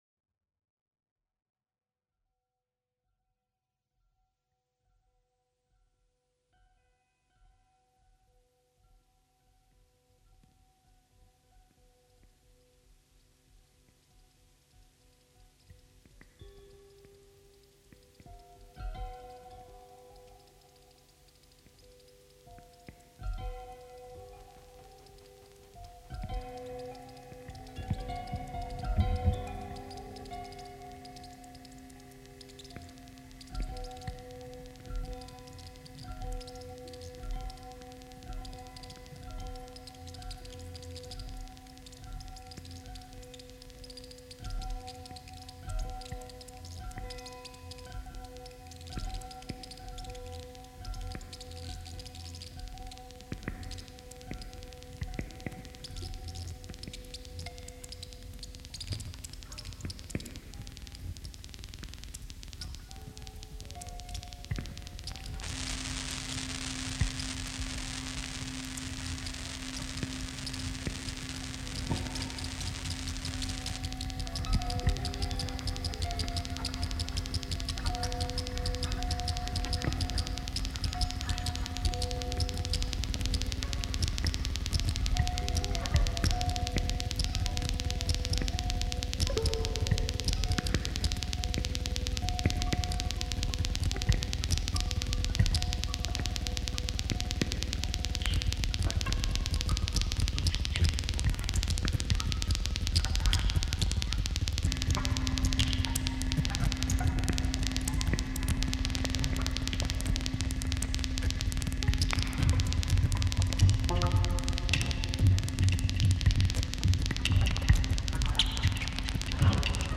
improvised and contemporary music
original, longer version